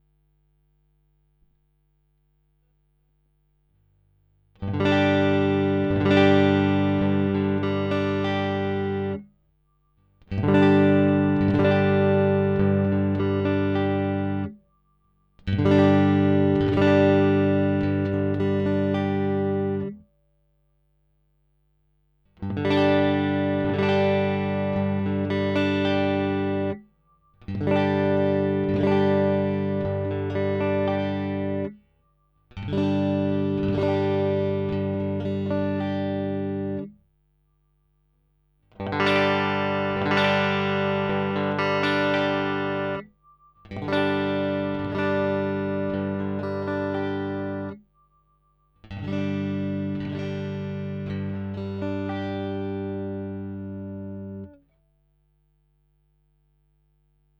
Verglichen wurde Pickslanting in folgender Reihenfolge:
0 Grad (annähernd)
45 Grad
90 Grad
Beginnend mit Hals-Pickup, danach Mittlerer Pickup und schließlich Bridge-Pickup
Gitarre: Fender Stratocaster (made in Mexico)
Plek_Winkel_DI-Box.wav